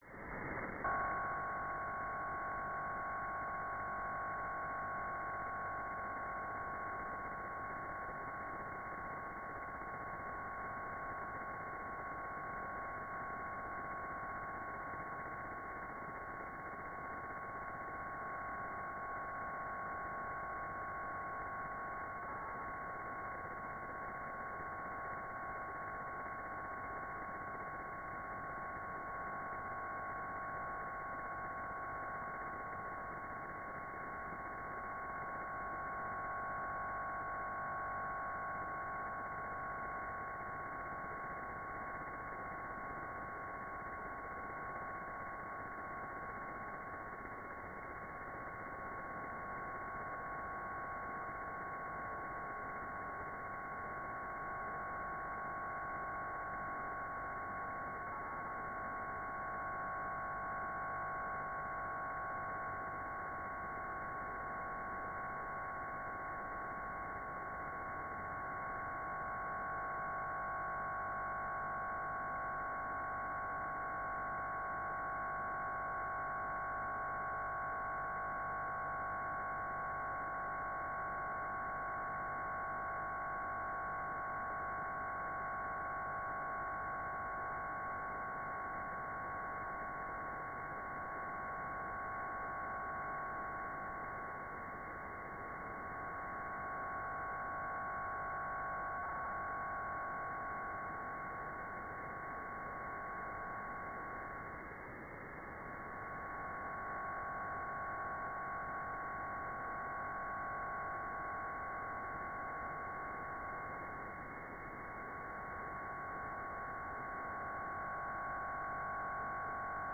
"transmitter_mode": "BPSK PMT-A3",